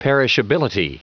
Prononciation du mot perishability en anglais (fichier audio)
Prononciation du mot : perishability